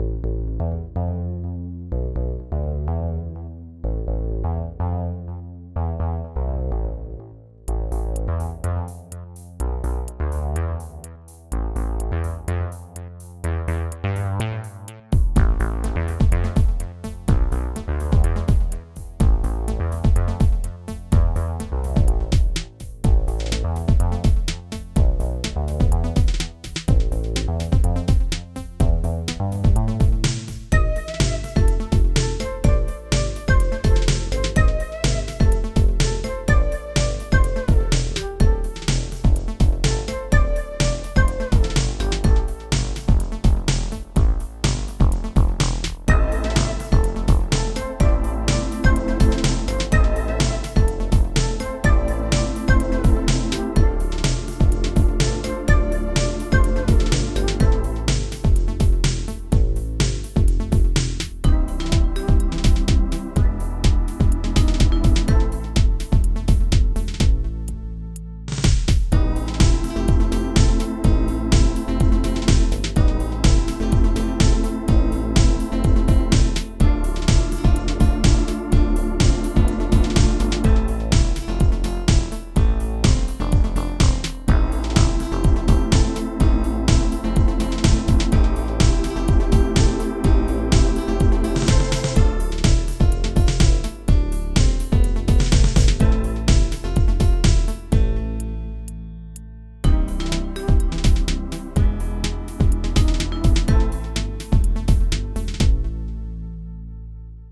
Jazzy theme suitable for a spy game.
• Music is loop-able, but also has an ending